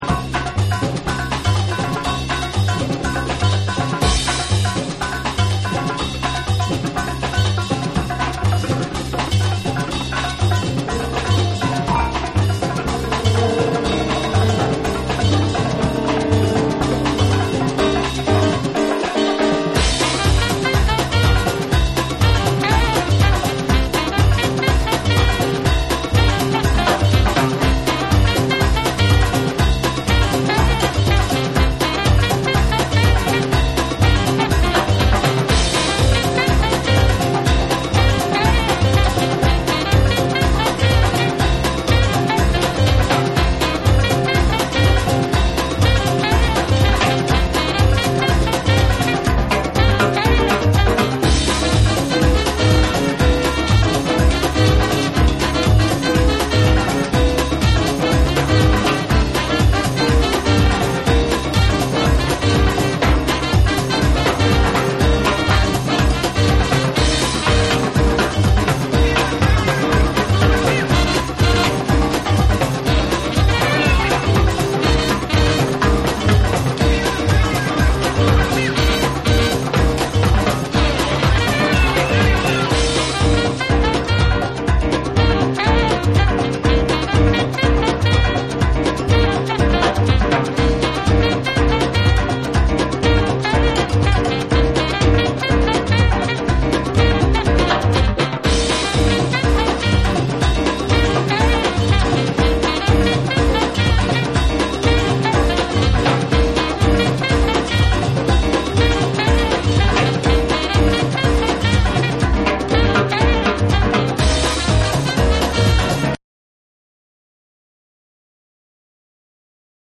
日本のクラブジャズ・ユニット
クラブ仕様のファットなリズムにサックスやトランペットなどの生楽器が絡み展開するラテン・ジャズ・ナンバー
JAPANESE / BREAKBEATS